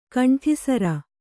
♪ kaṇṭhisara